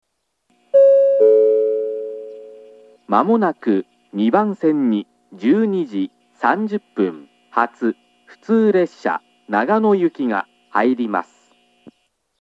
２番線接近予告放送 12:30発普通長野行の放送です。
接近放送の流れる前に流れます。